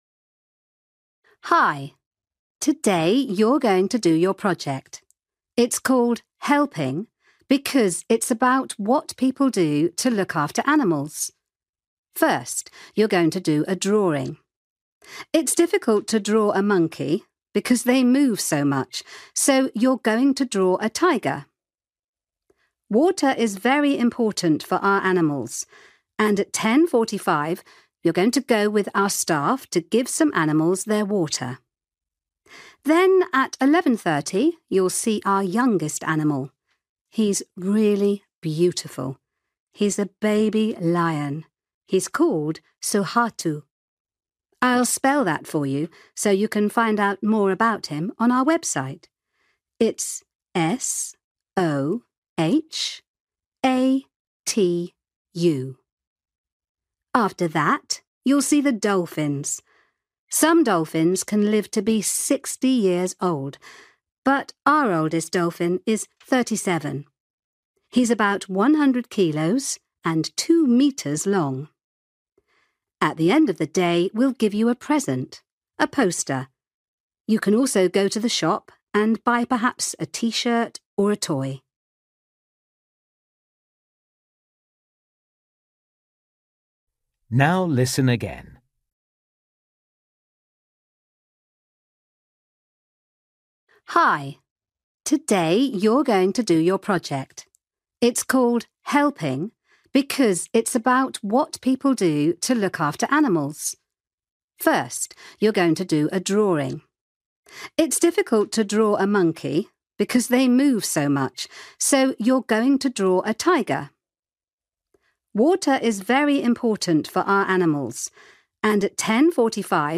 You will hear a woman telling some students about their visit to the zoo.